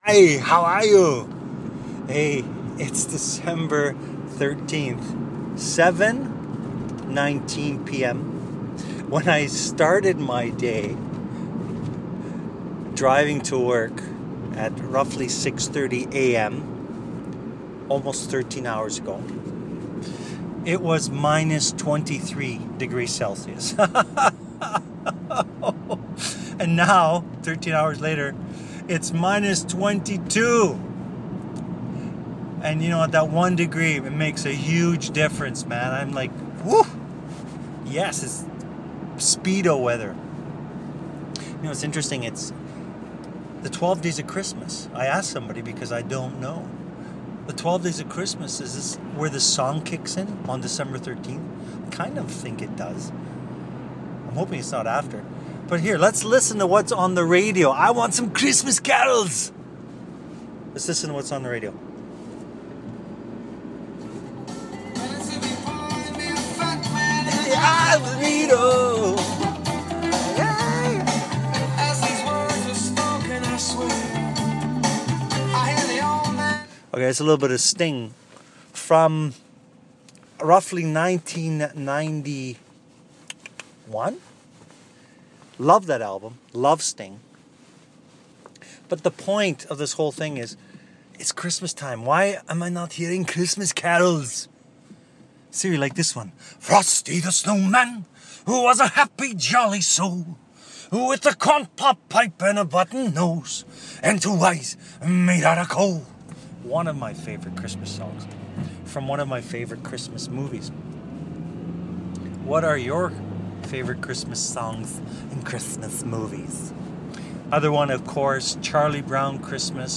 After work in my car and cold.